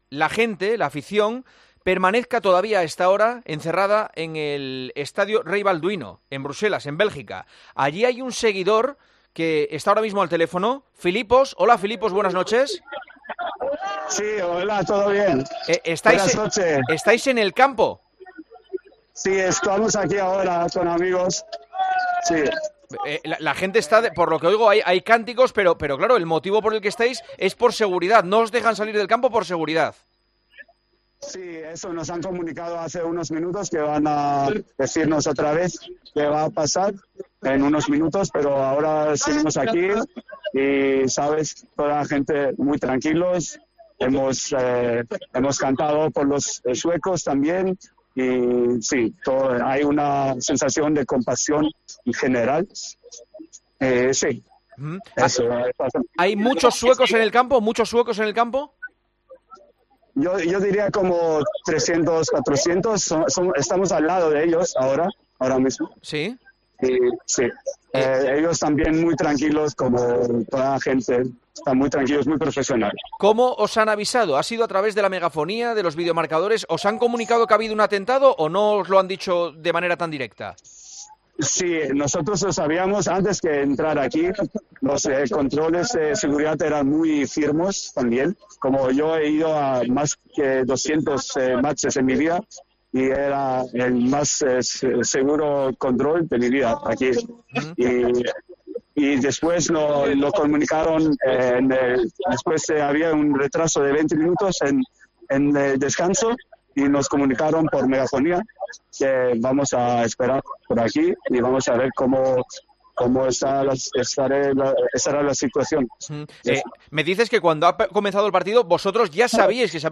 Un espectador del Bélgica - Suecia cuenta en directo cómo se vive el atentado dentro del estadio